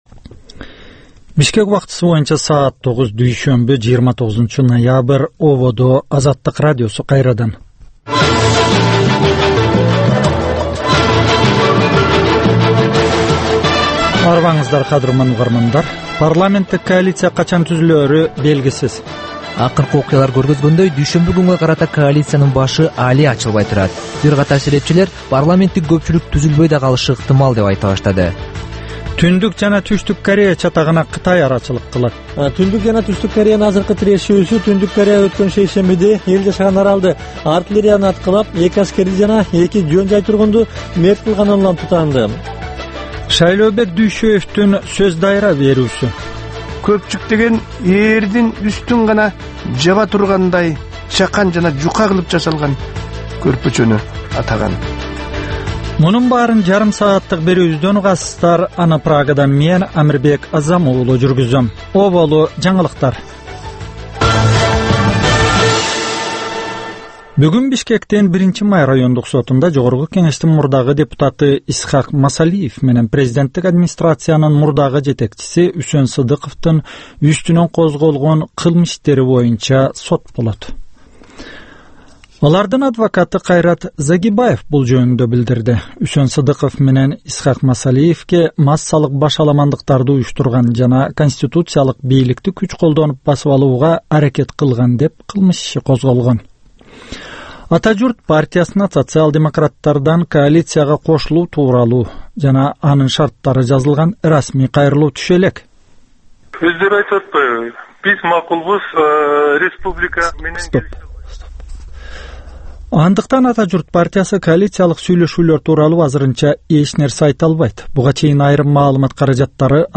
Таңкы 9дагы кабарлар